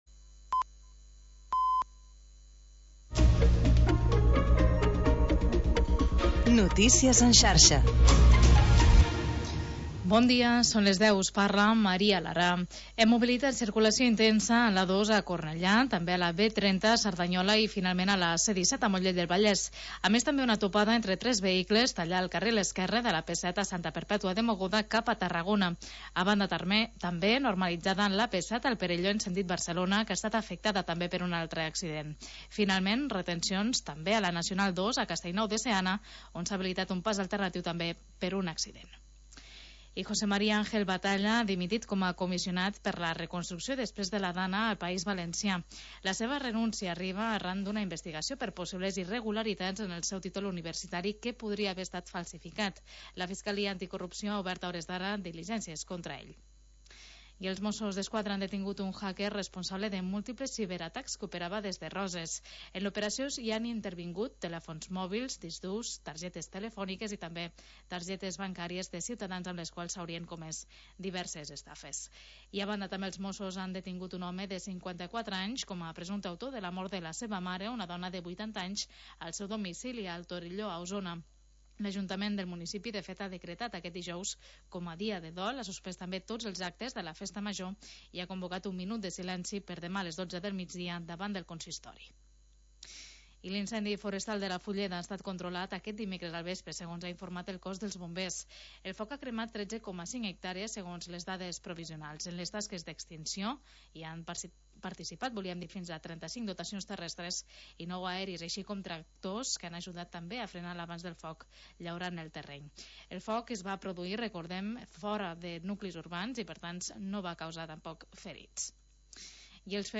Magazín territorial d'estiu